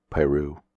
Lake Piru (/ˈpr/
En-us-piru.ogg.mp3